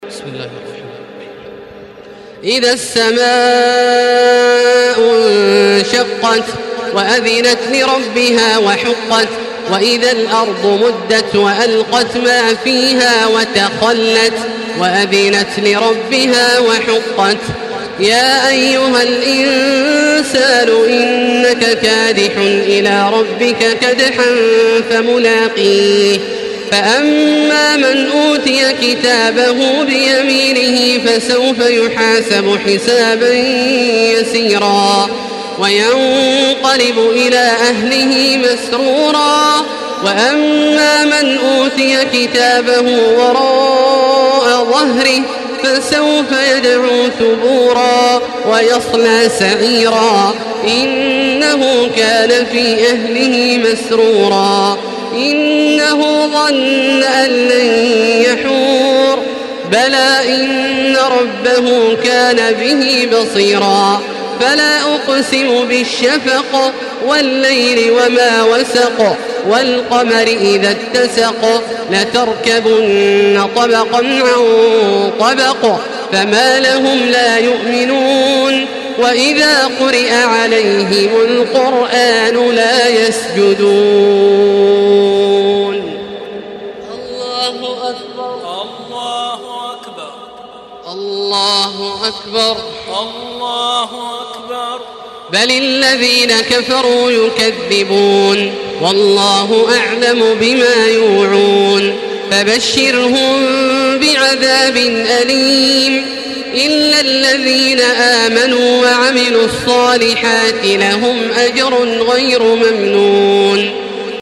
تحميل سورة الانشقاق بصوت تراويح الحرم المكي 1435
مرتل